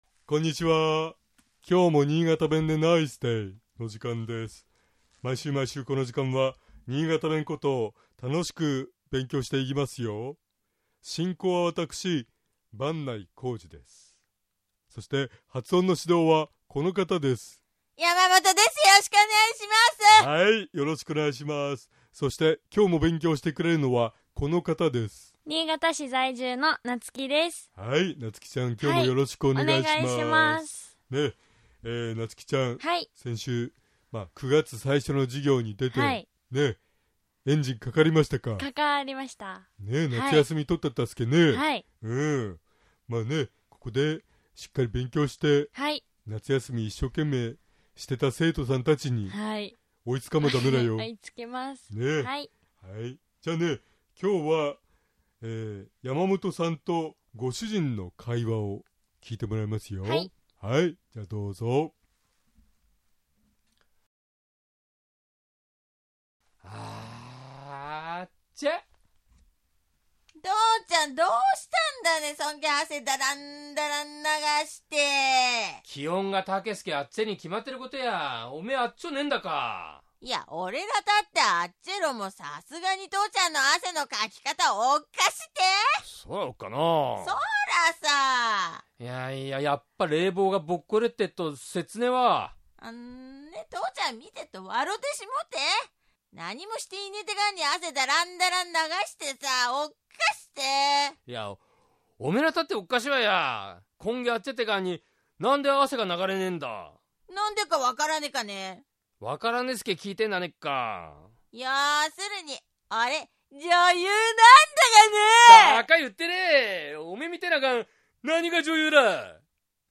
尚、このコーナーで紹介している言葉は、 主に新潟市とその周辺で使われている方言ですが、 それでも、世代や地域によって、 使い方、解釈、発音、アクセントなどに 微妙な違いがある事を御了承下さい。 130909niigataben.mp3 ポッドキャ